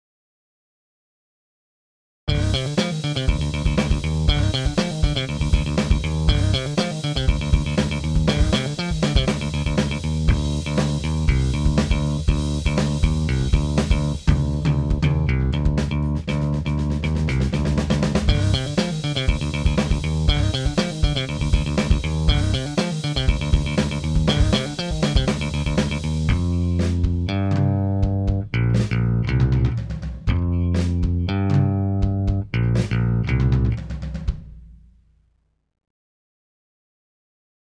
Dabei handelt es sich um einen fünfsaitigen Bass, der sich vor allen Dingen für Rock und Metal eigenen soll.
Für die Klangbeispiele habe ich Bassläufe mit unterschiedlichen Presets eingespielt.